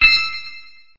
s_ui_buy.mp3